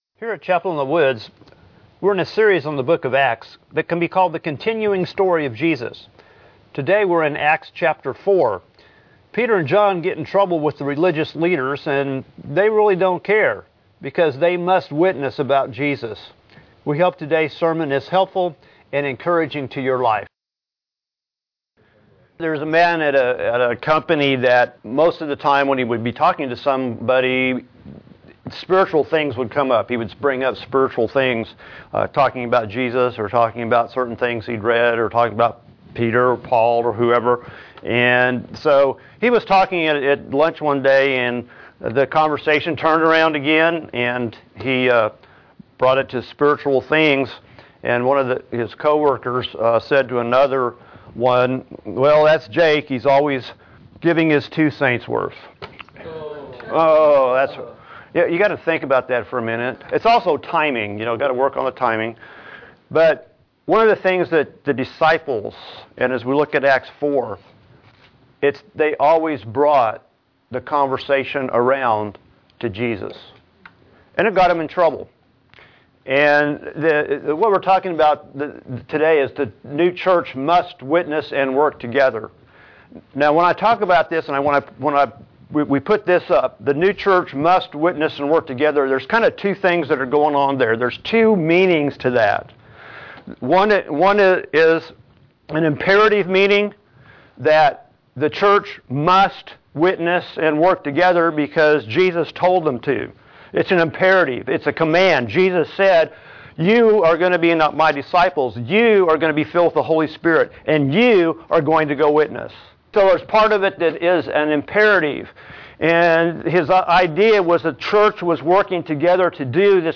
Witness anyway MP3 SUBSCRIBE on iTunes(Podcast) Notes Discussion Sermons in this Series The religious leaders don't like the Jesus following riff raff teaching the people.